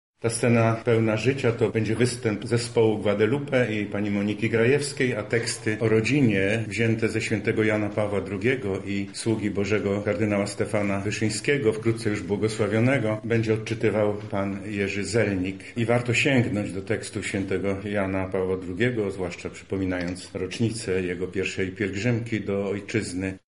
Więcej o wydarzeniu mówi abp. Stanisław Budzik: